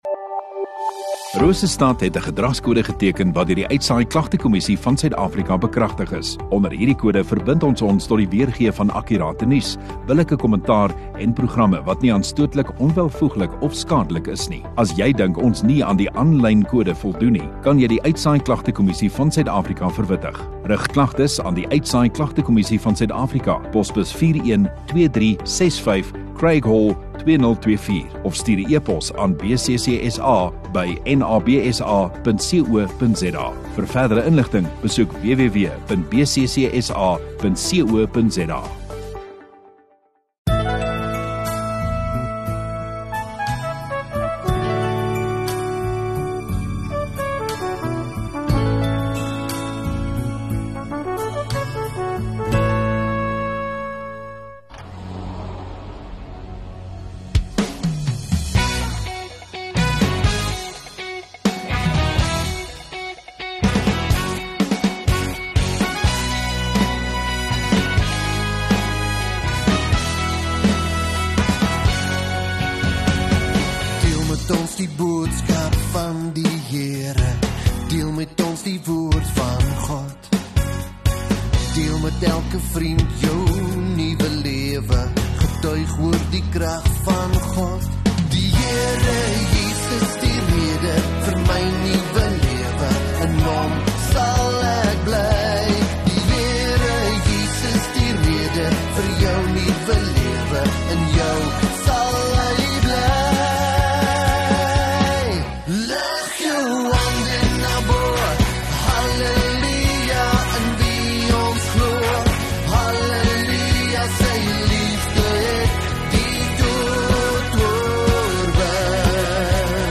24 Mar Sondagoggend Erediens